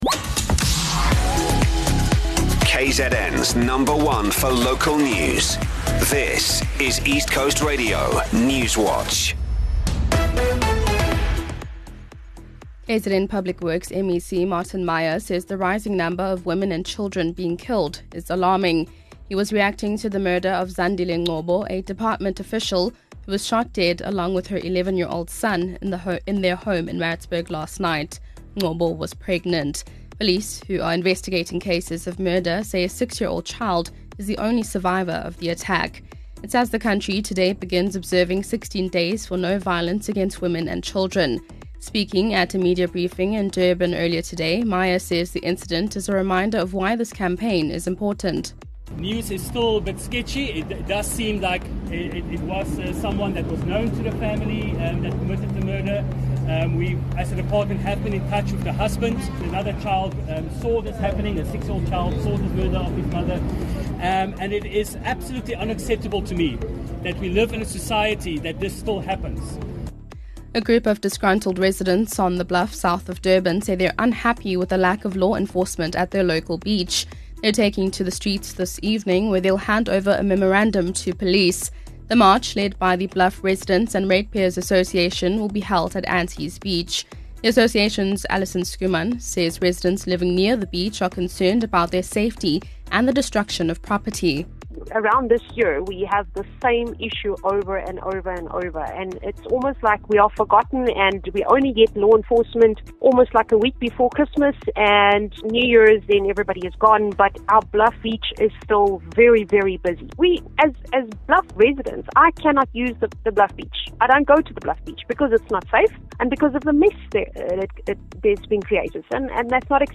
We are KwaZulu-Natal’s trusted news source with a focus on local, breaking news. Our bulletins run from 6am until 6pm, Monday to Friday.